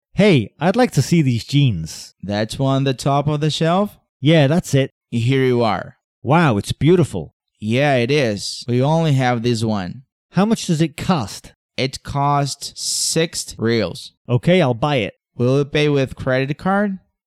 Conversações em Inglês para iniciantes